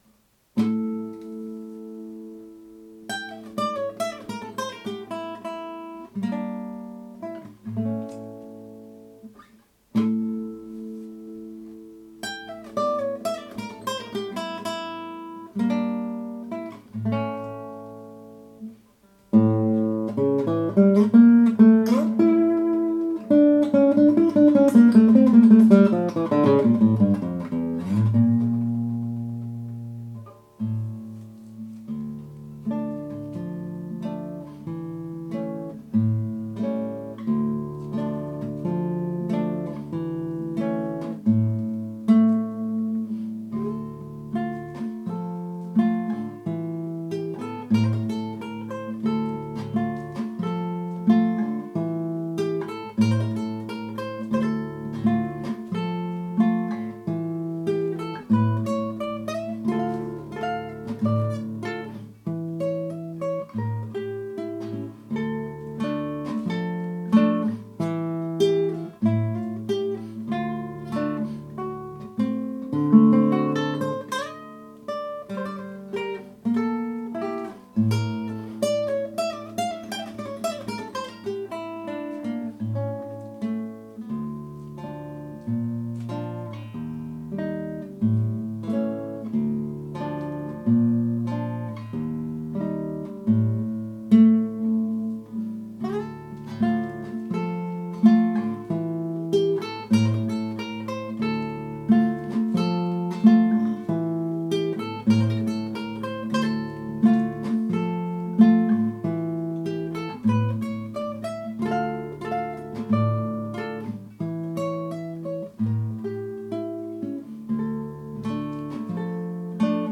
This is a live recording I did as part of my preparation for my A.T.C.L Diploma in Classical Guitar Performance.